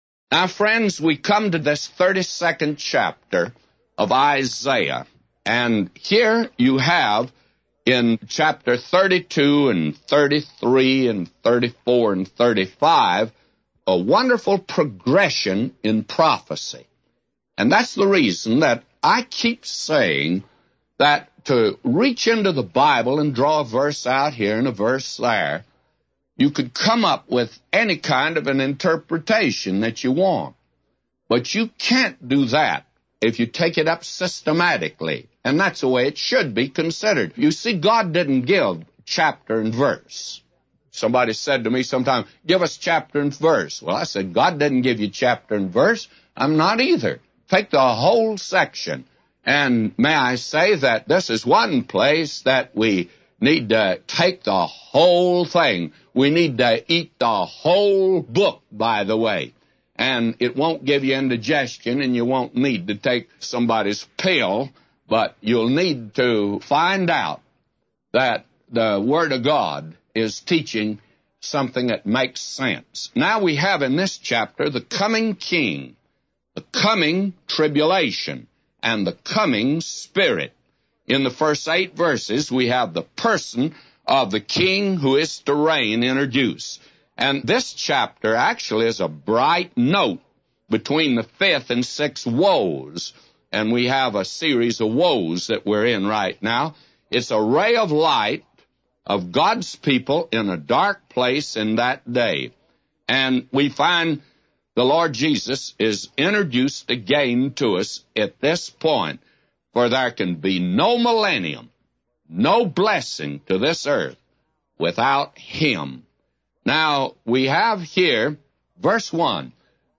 A Commentary By J Vernon MCgee For Isaiah 32:1-999